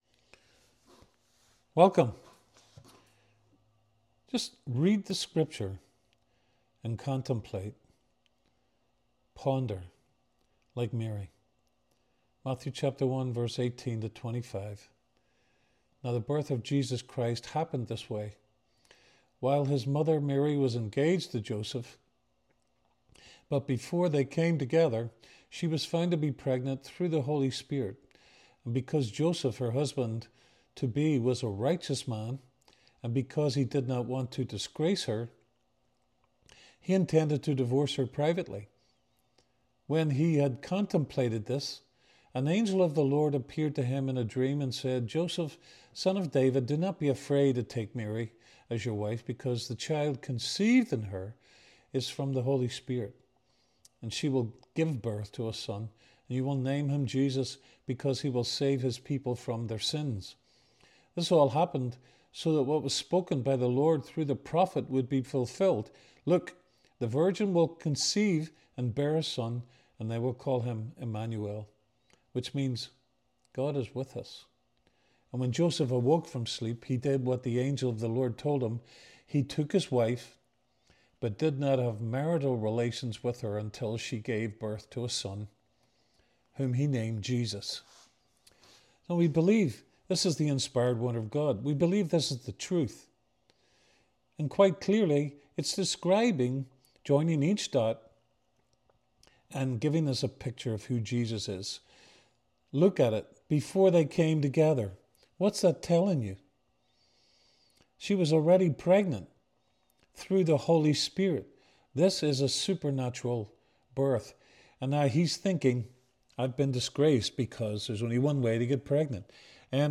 A Christmas devotional